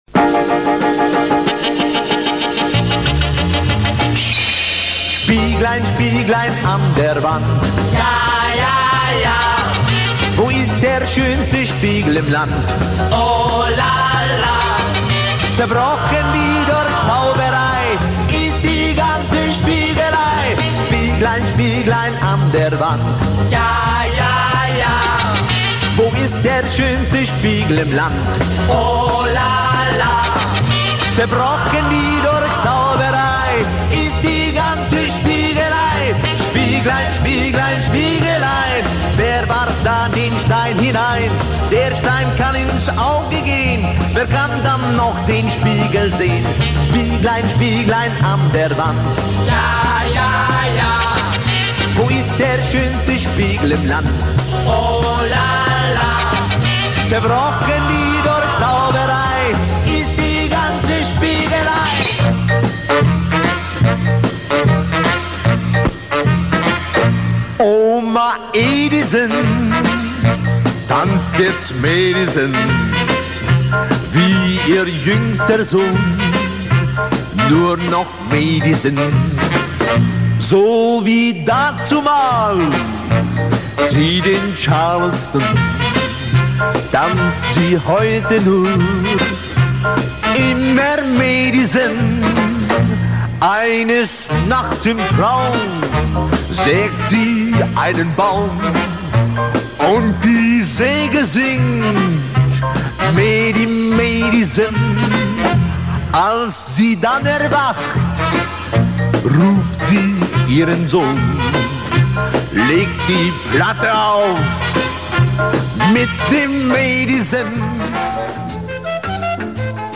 on piano accompanying combo